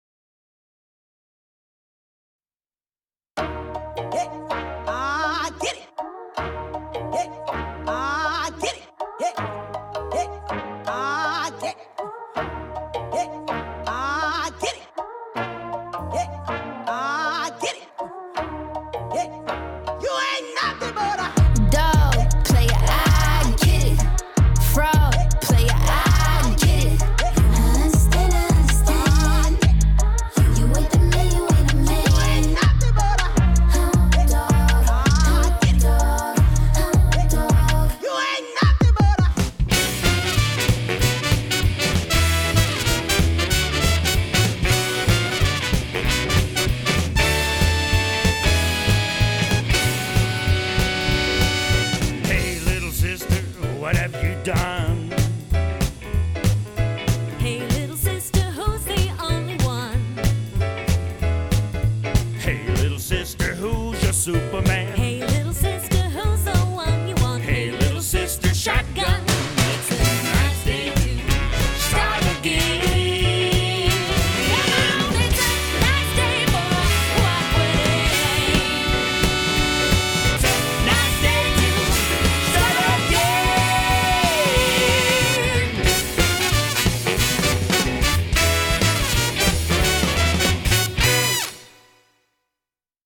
Audition_BQ-Jazz.mp3